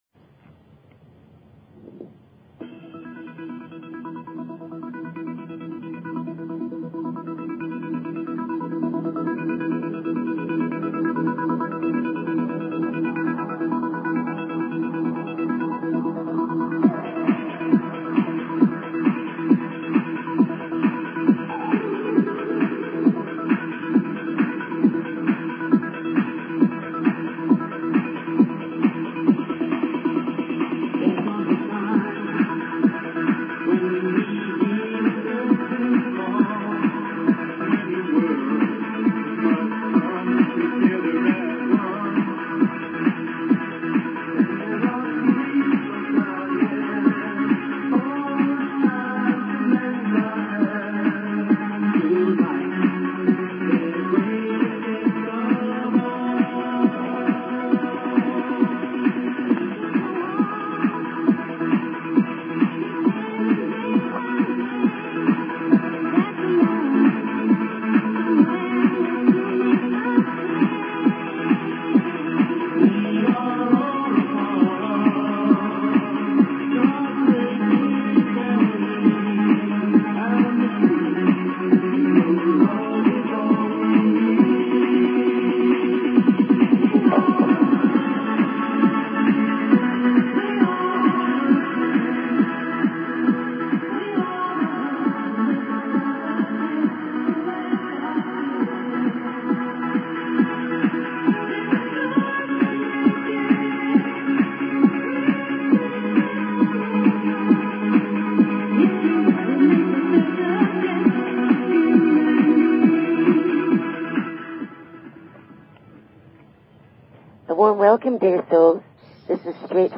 Talk Show Episode, Audio Podcast, Straight_from_the_Heart and Courtesy of BBS Radio on , show guests , about , categorized as